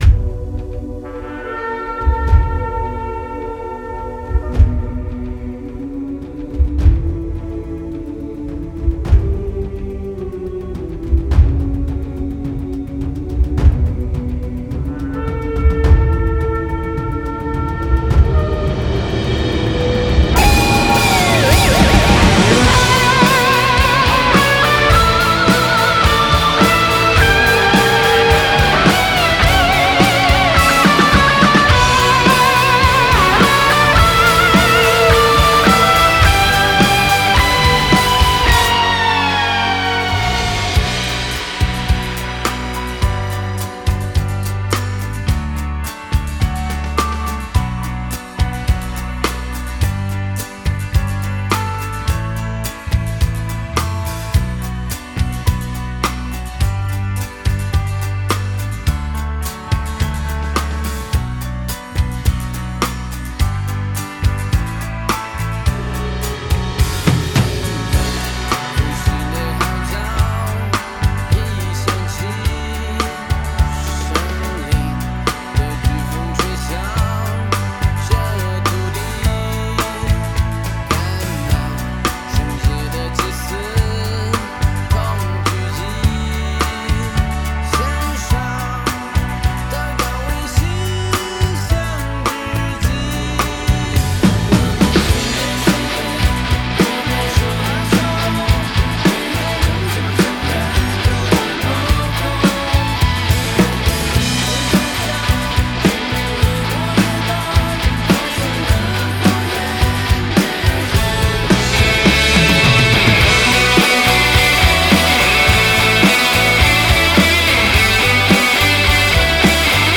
mp3 伴唱音樂